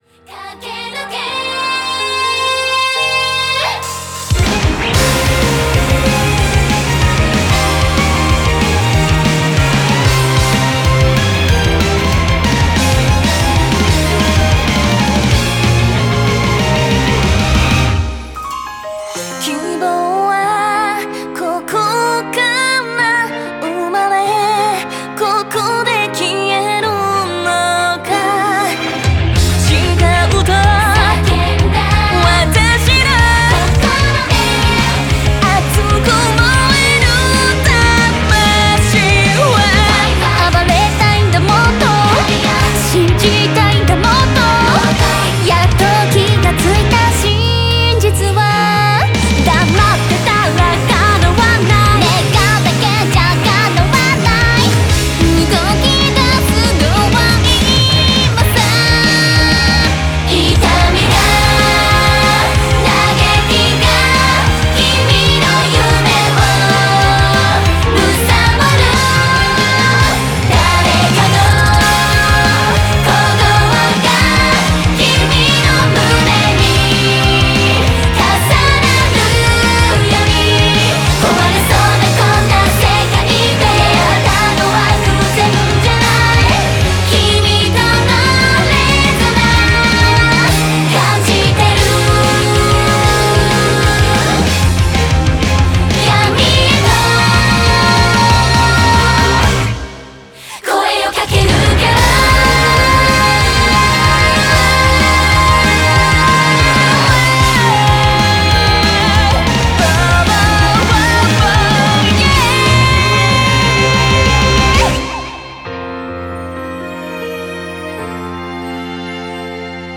Audio QualityPerfect (High Quality)
Song type: Anime